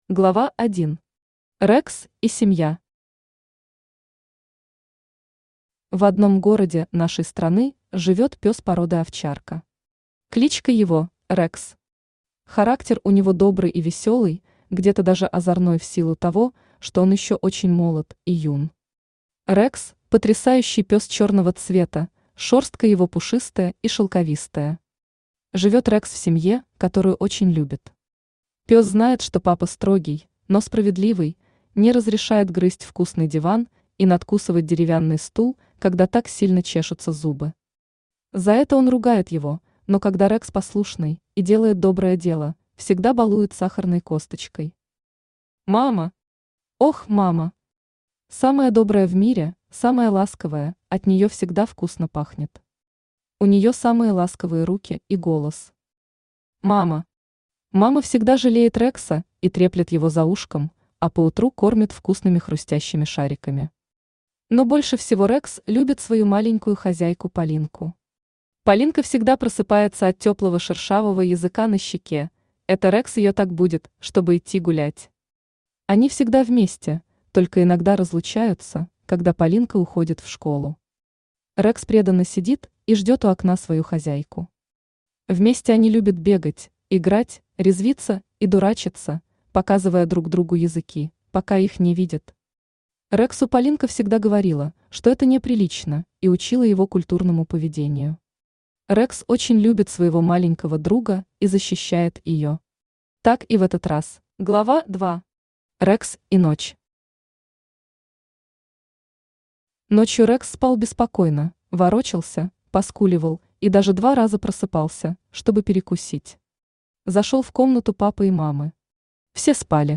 Аудиокнига Рекс. Начало большой истории | Библиотека аудиокниг
Начало большой истории Автор Оксана Яновна Иванова Читает аудиокнигу Авточтец ЛитРес.